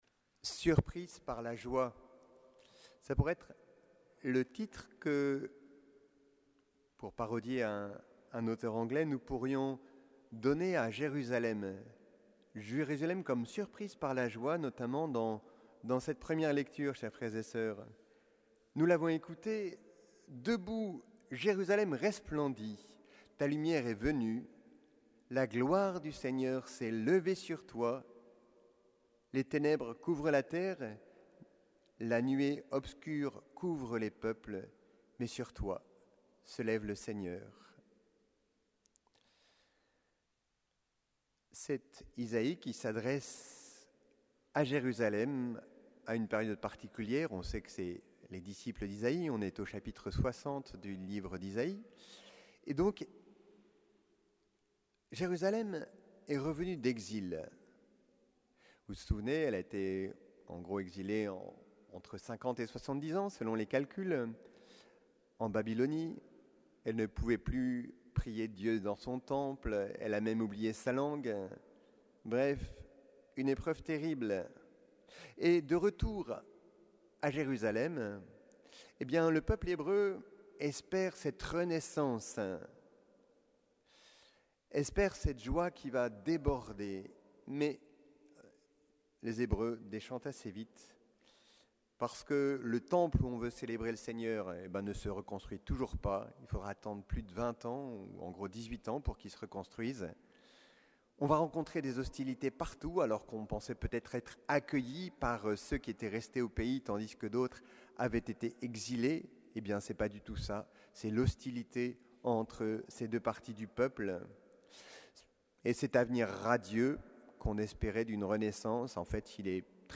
Homélie de la solennité de l'Épiphanie du Seigneur